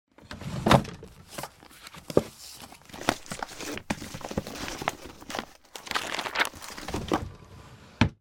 bookcase_1.ogg